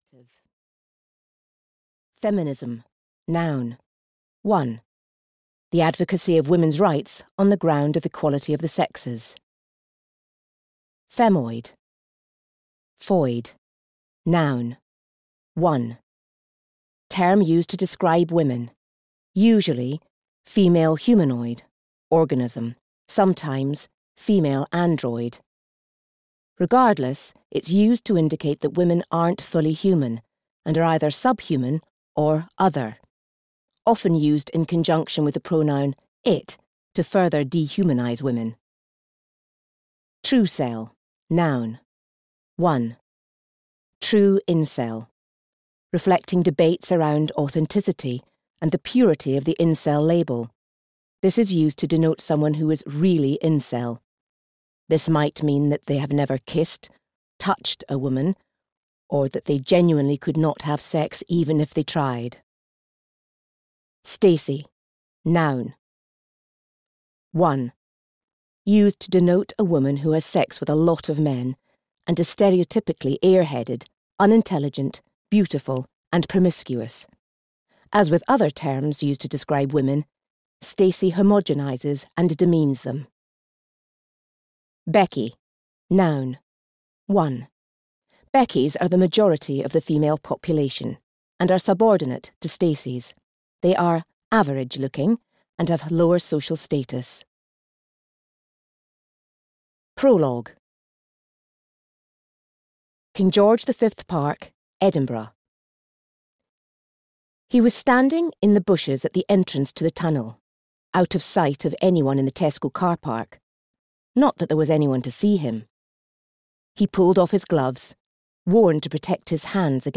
Open Your Eyes (EN) audiokniha
Ukázka z knihy